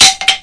描述：金属